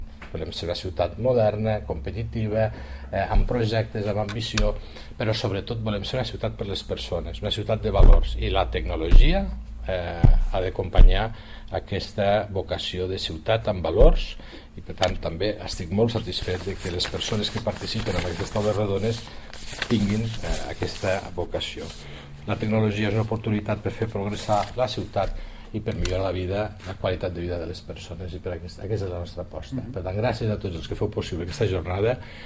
tall-de-veu-de-lalcalde-felix-larrosa-sobre-la-mobile-week-lleida-2019